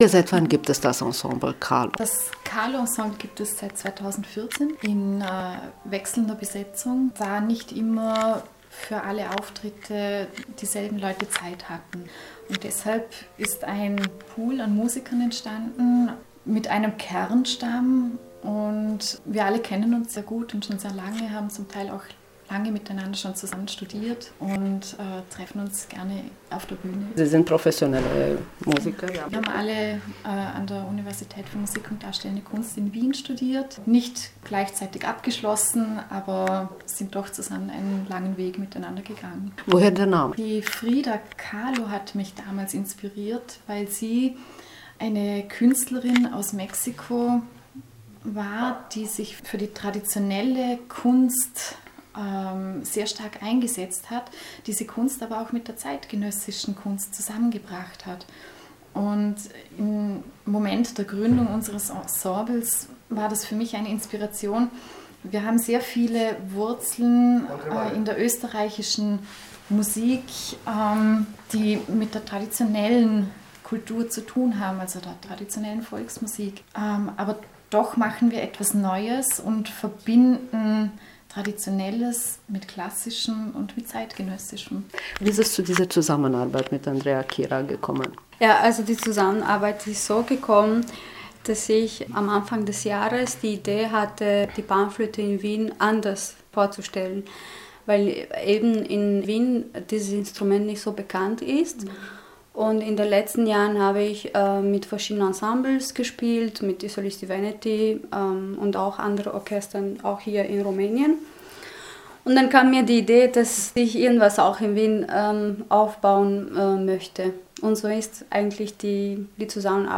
Winterkonzert mit Panflöte in Bukarest
Ein besonderes Konzert war im Dezember im Bukarester Radiosaal zu hören.